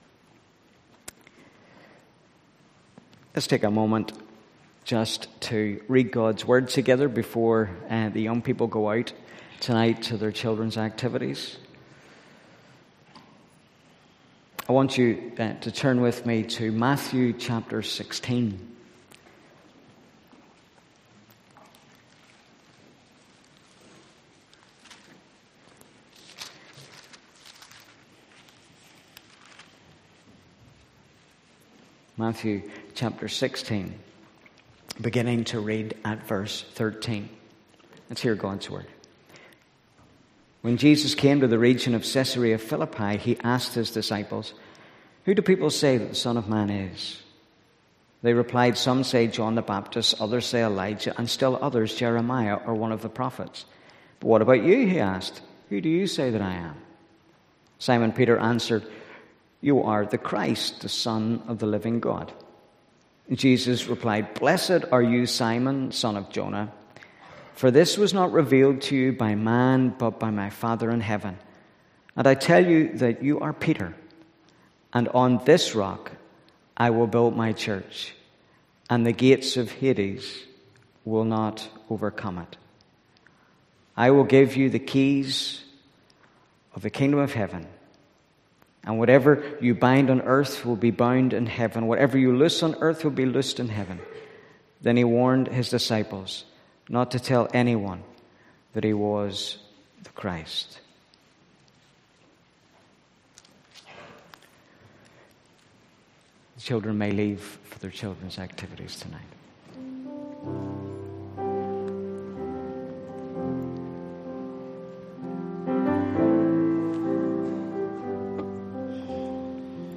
Service Type: pm